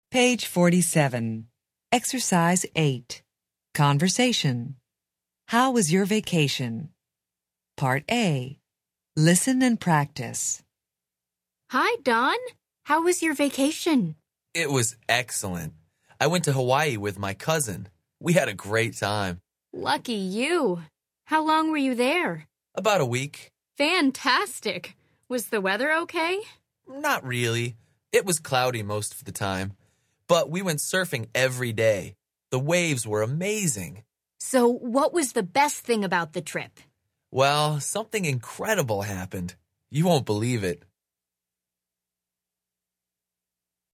Interchange Third Edition Level 1 Unit 7 Ex 8 Conversation Track 21 Students Book Student Arcade Self Study Audio